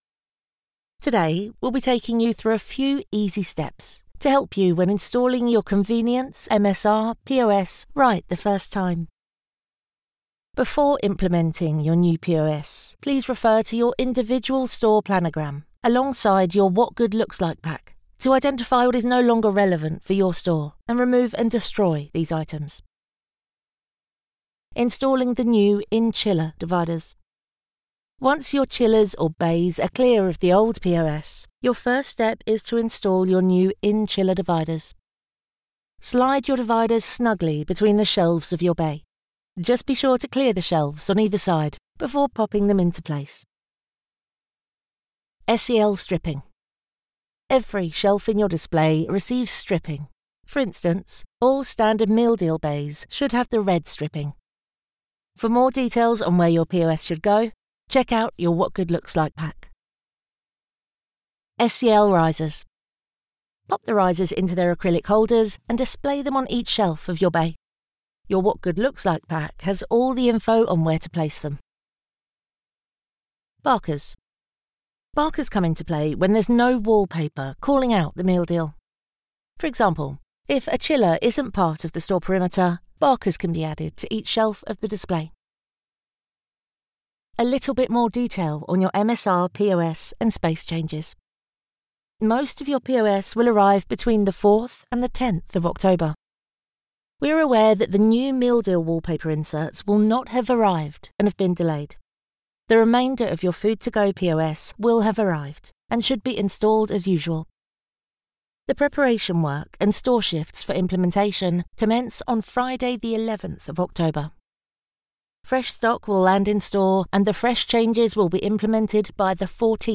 English (British)
Natural, Distinctive, Accessible, Versatile, Warm
E-learning